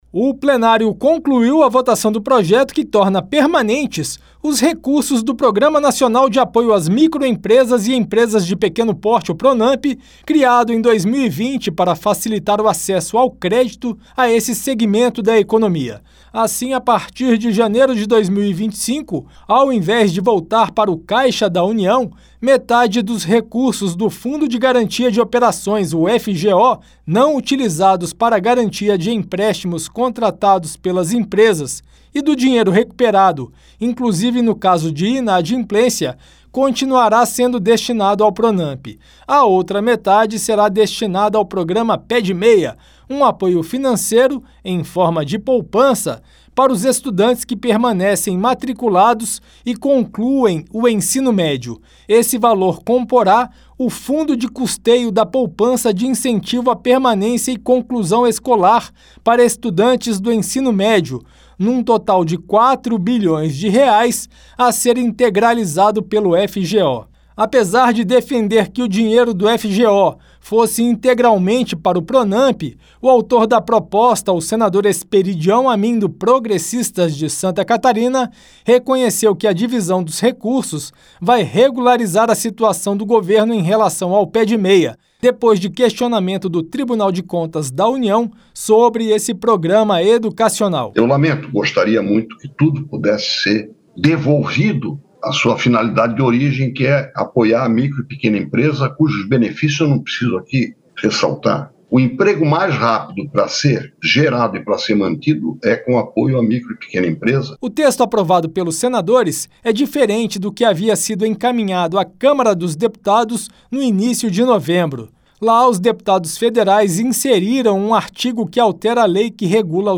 Senador Esperidião Amin